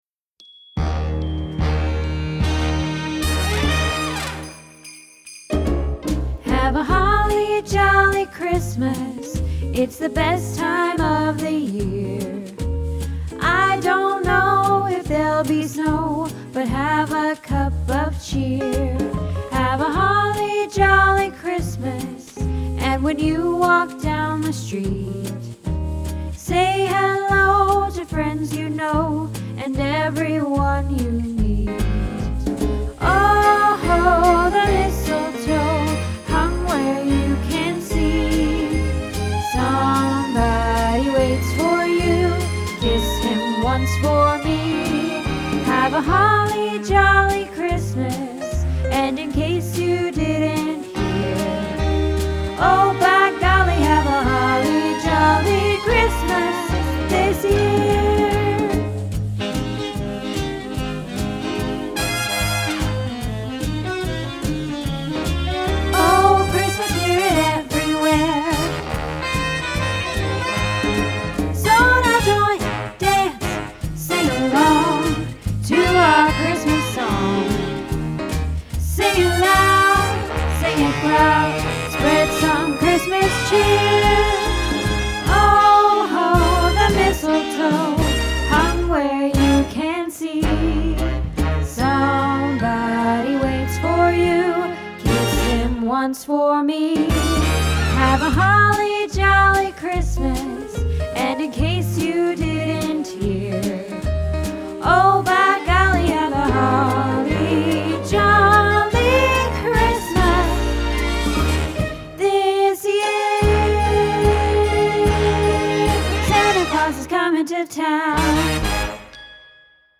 Holly Jolly Christmas - Alto